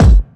GS Phat Kicks 023.wav